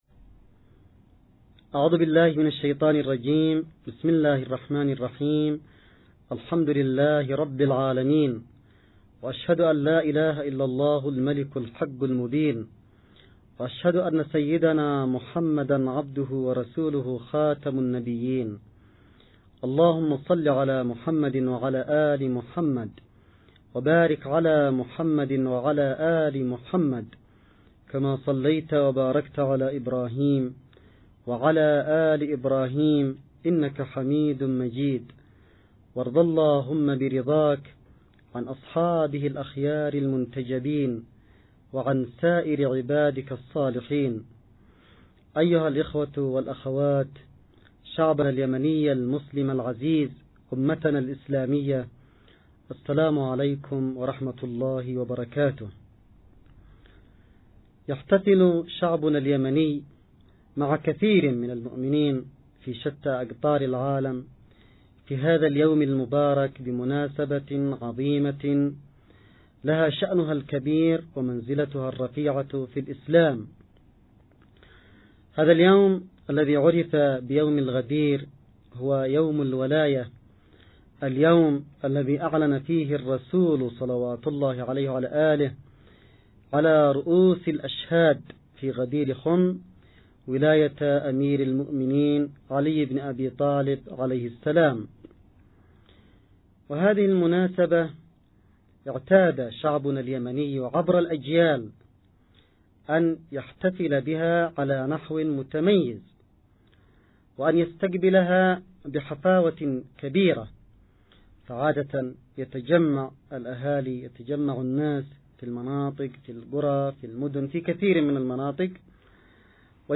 نص+ أستماع لكلمة السيد الحوثي بمناسبة يوم الولاية 1438هـ